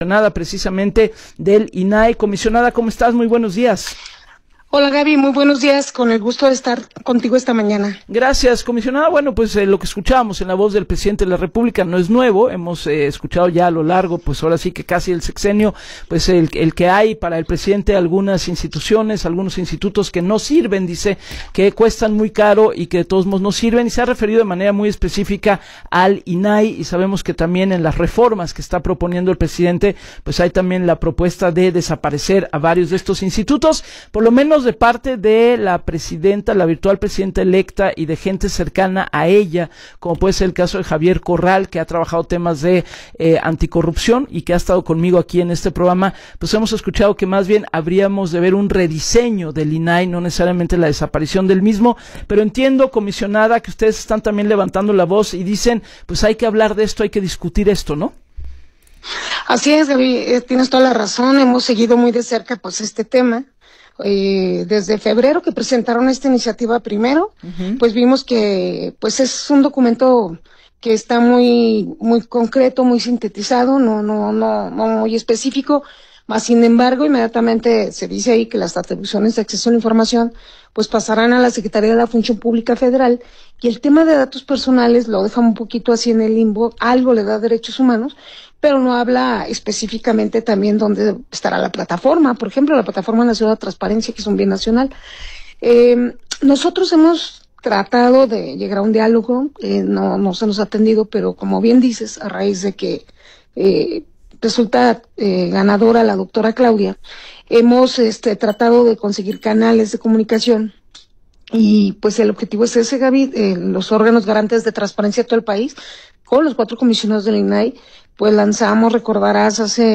Entrevista Gabriela Warkentin